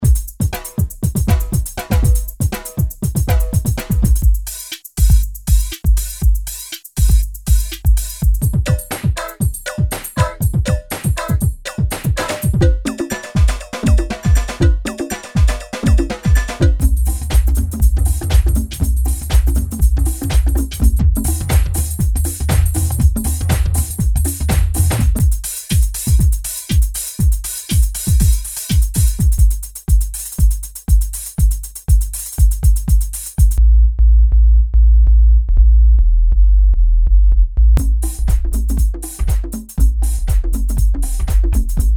gurgling pad